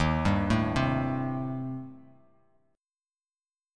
D#6b5 chord